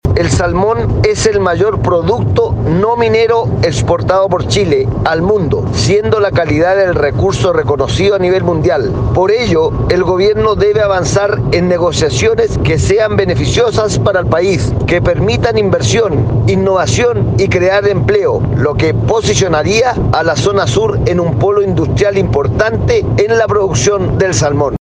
Al respecto el parlamentario por Chiloé, Fernando Bórquez, señaló: